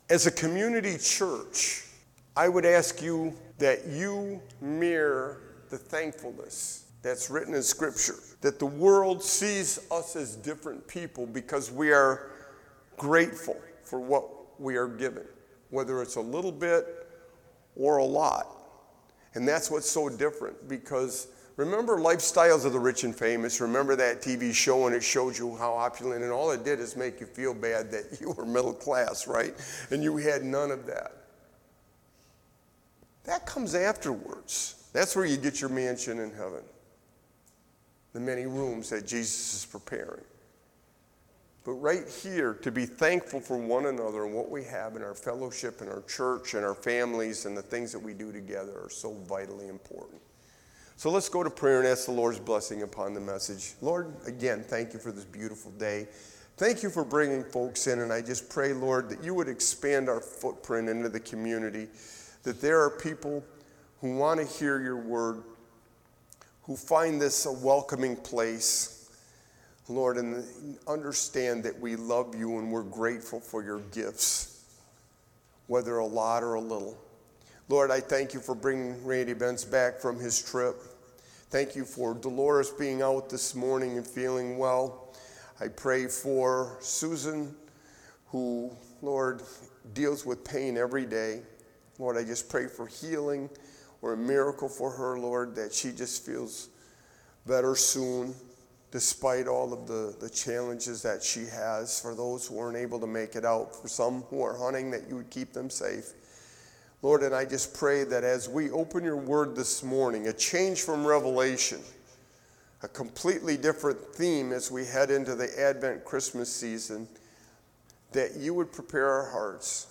Psalm 23:2-6; The shepherd theme in Scripture is precious to members of God’s flock….during good times and bad times. This Thanksgiving message is timeless in scope and depth, as David knew well the nature of sheep and the character of a good shepherd.